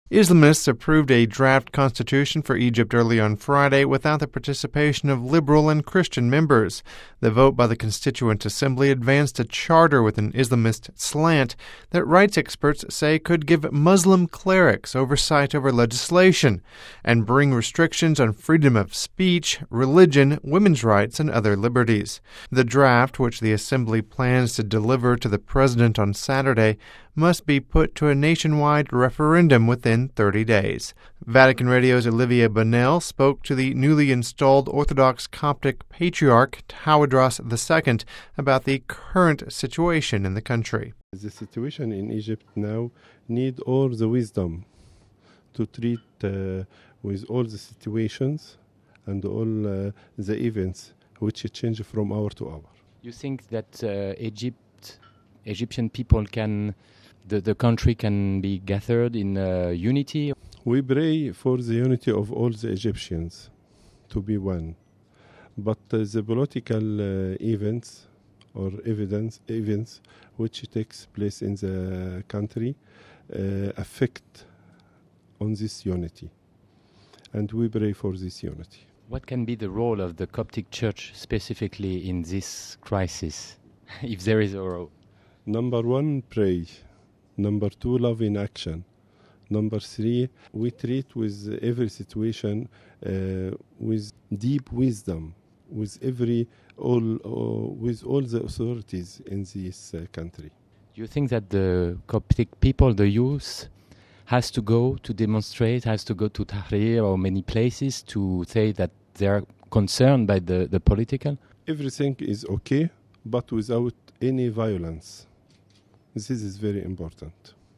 Asked what can be the role of the Coptic Church in this crisis, the Patriarch responding by saying, “Number one, pray; number two, love in action; number three, we treat with every situation with deep wisdom.”